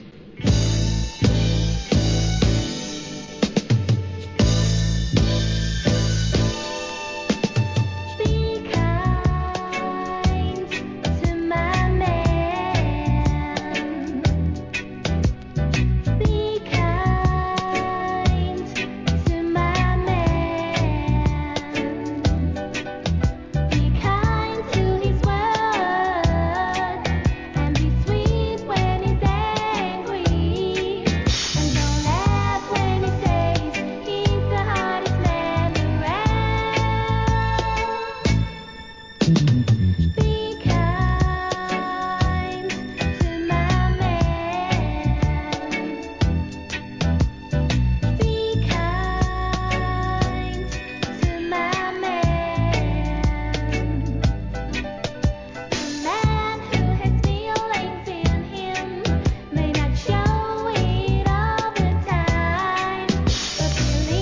REGGAE
ムーディーなLOVERS REGGAEを集めた好コンピ第8弾!!